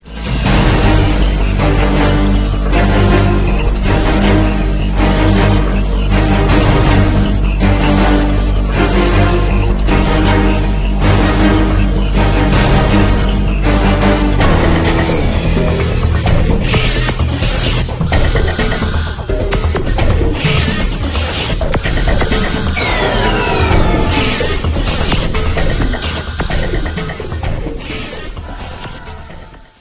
cool, action cue from the main title!